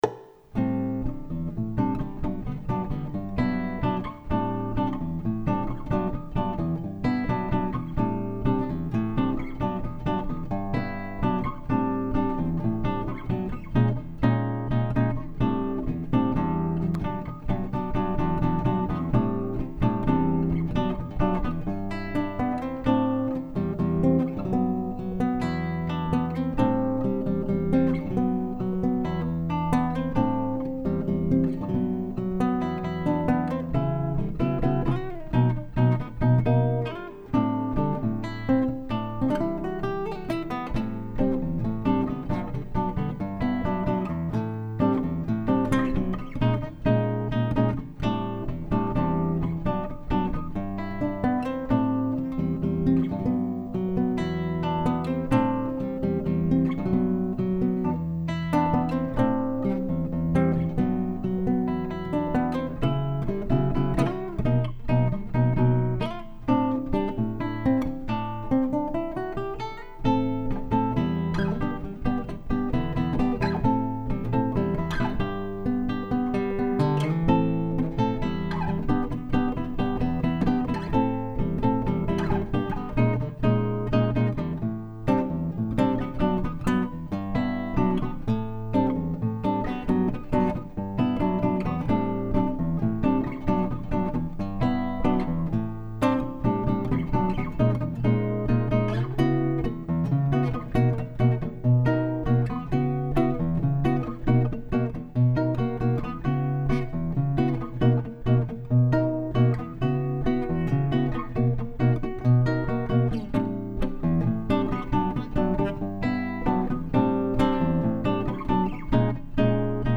Original Guitar Music
samba-like jazzish piece